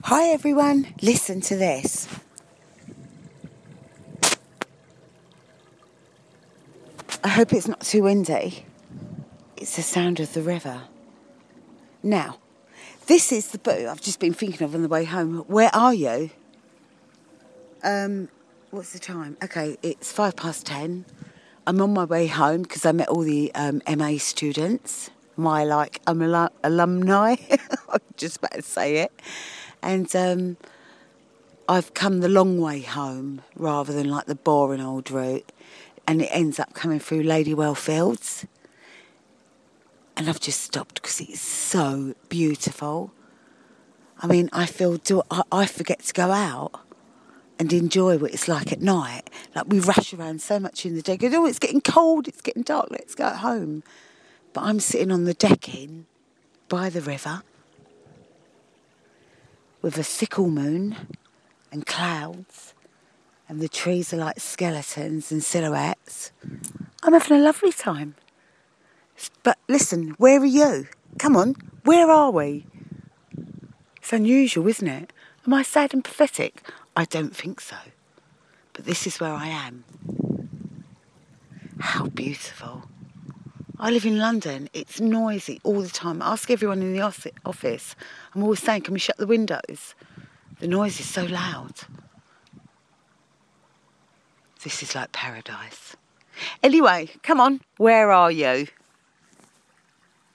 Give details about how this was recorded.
It's 10.00pm and I'm in the park.